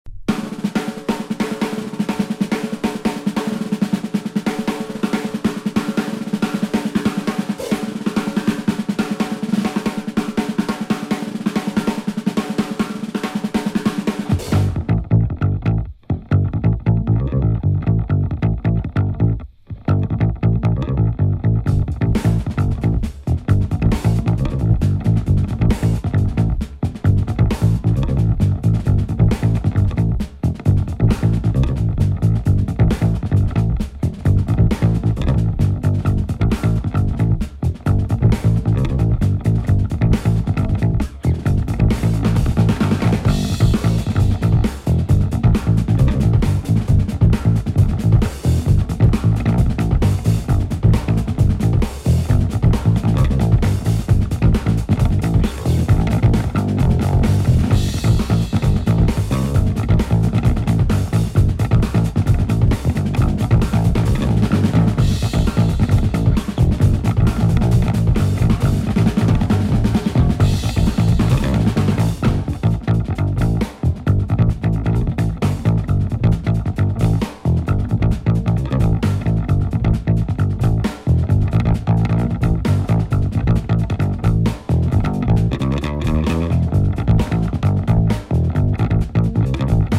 無茶苦茶格好良いビートが多数!!